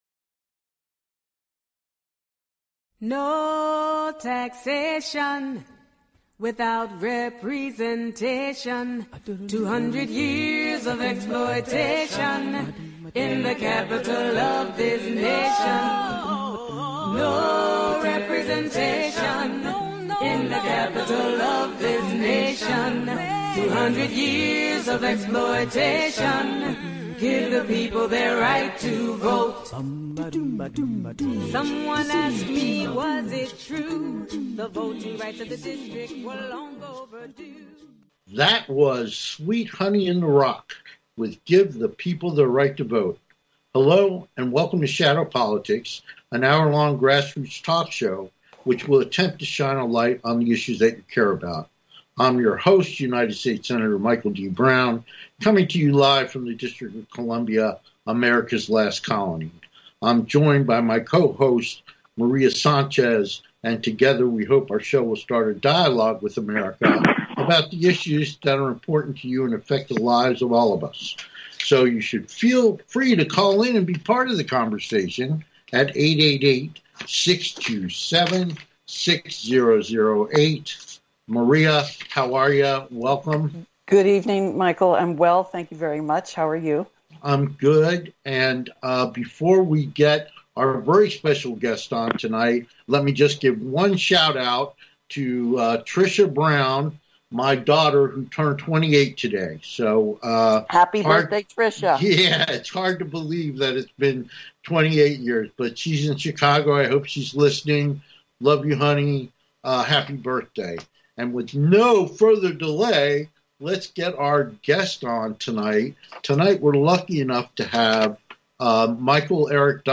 Talk Show Episode
Guest, Michael Eric Dyson